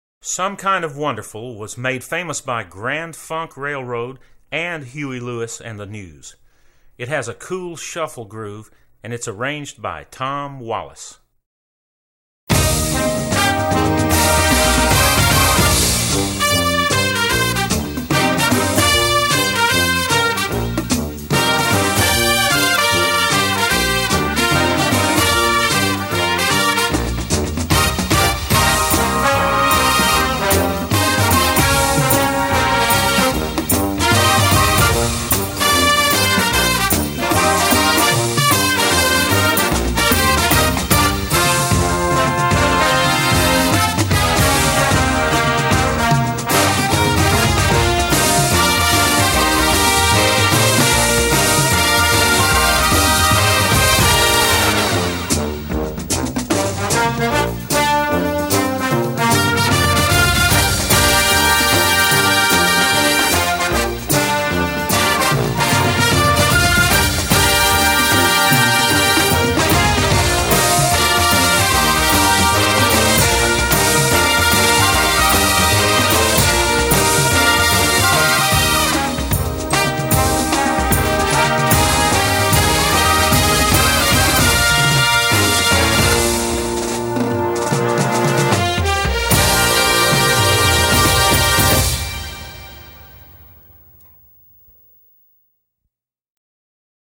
Voicing: Marching Band